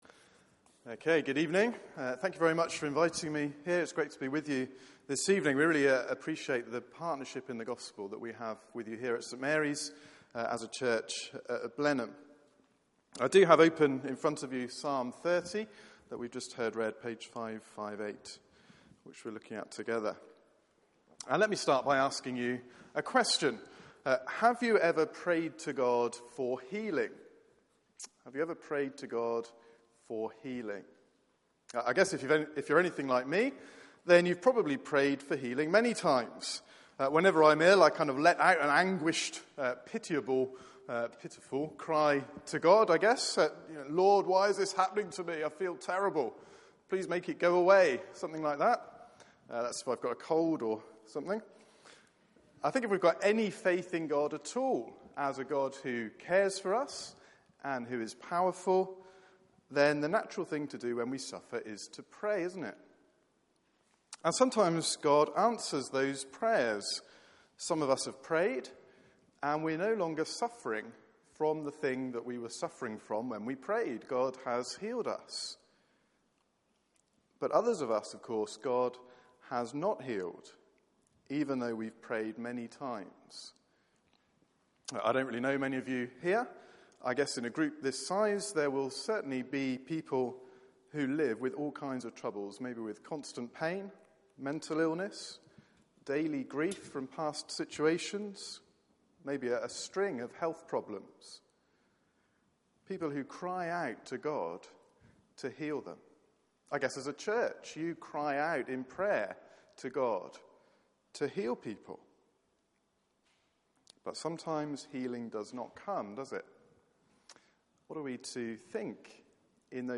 Passage: Psalm 30 Service Type: Weekly Service at 4pm